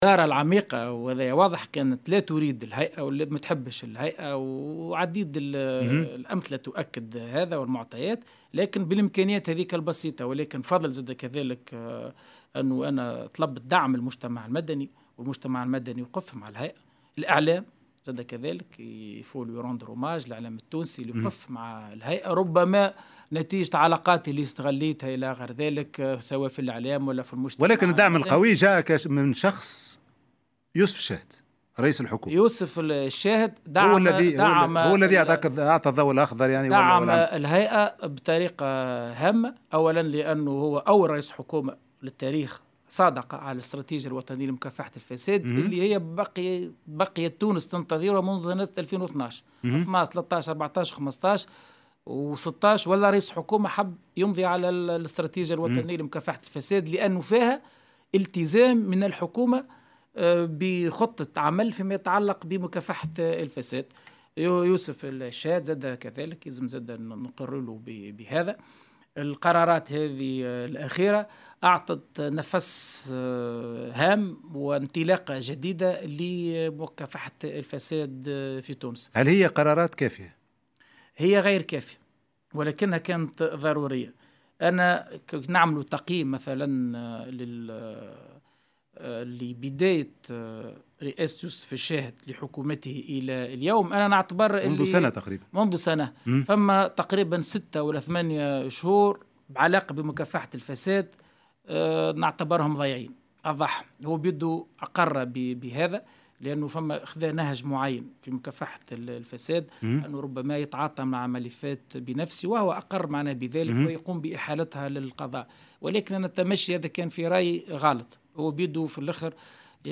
في حوار مع قناة "فرانس 24"